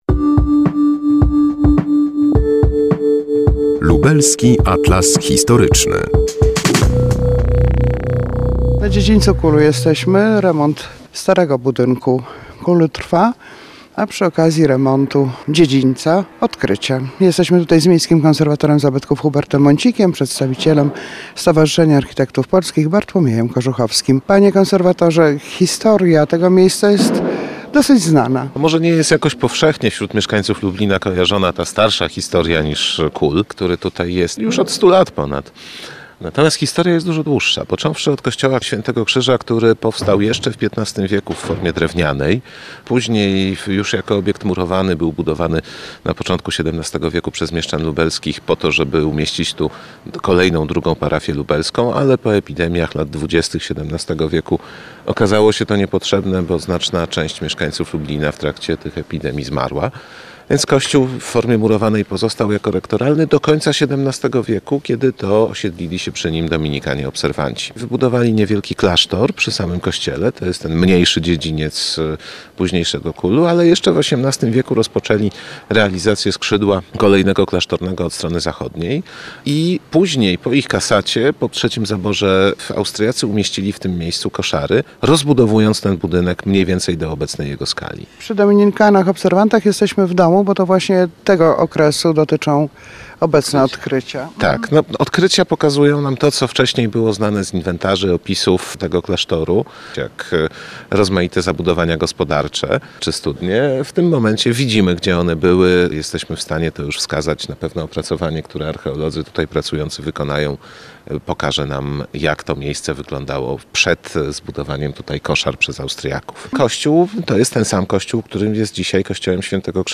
Dziś jesteśmy na dziedzińcu KUL. Trwa remont starego budynku, a przy jego okazji - odkrycia.